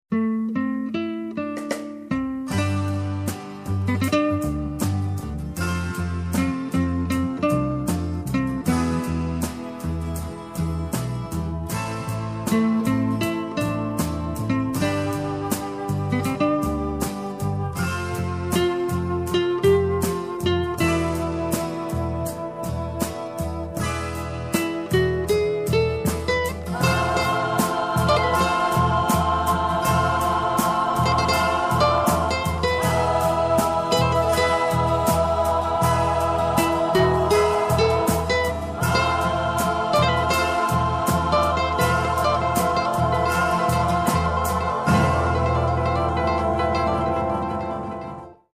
Spaghetti Western epic medium instr.